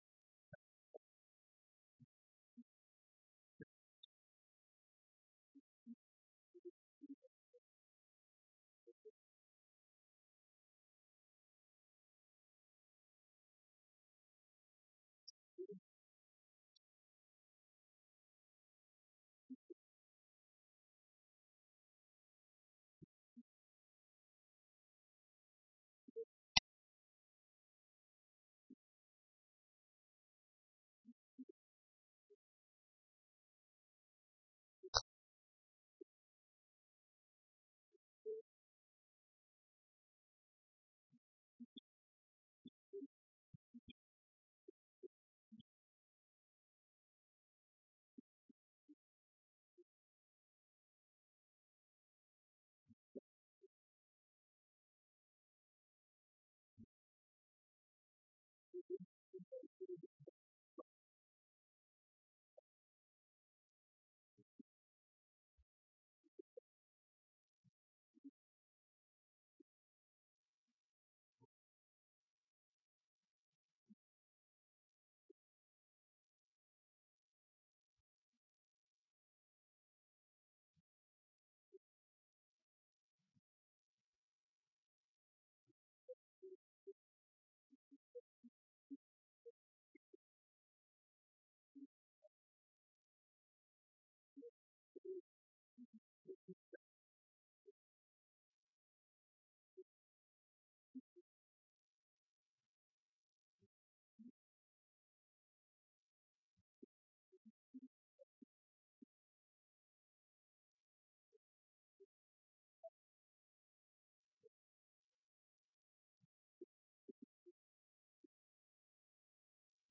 Passage: Luke 23:35-43 Service Type: Sunday
Sermon Only